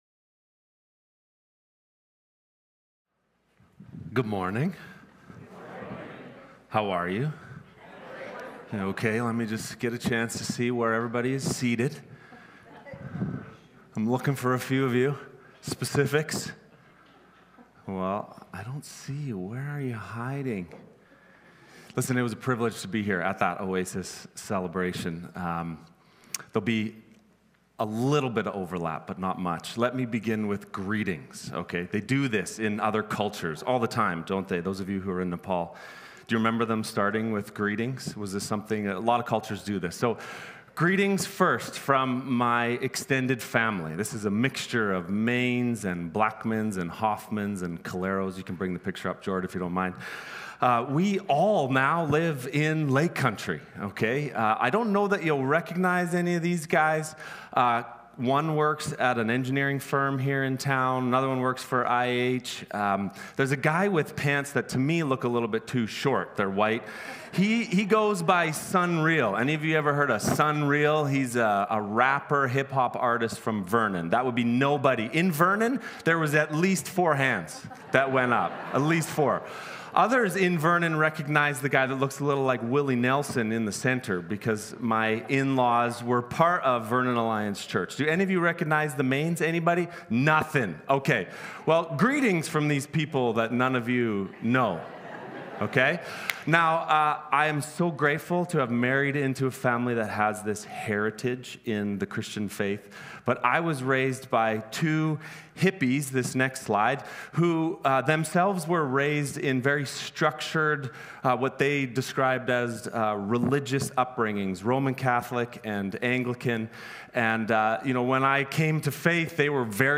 SERMONS | Mission Creek Alliance Church
Guest Speaker